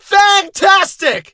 fang_kill_vo_01.ogg